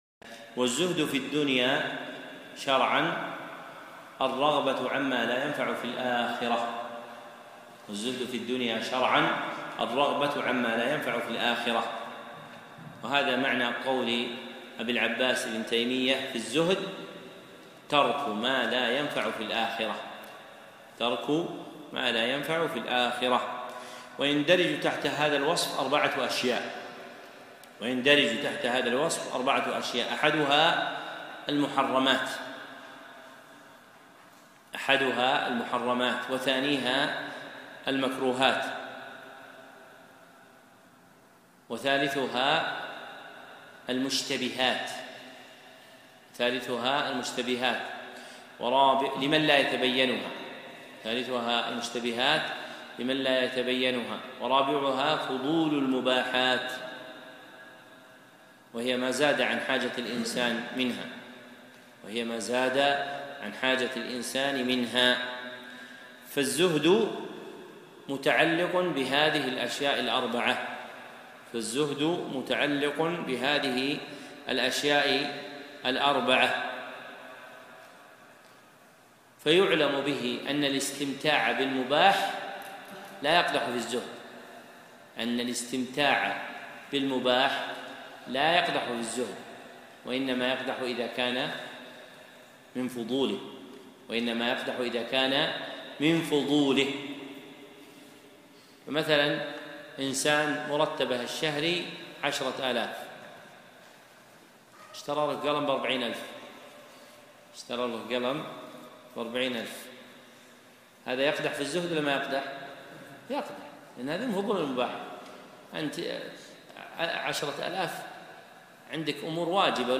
الزهد موعظة بليغة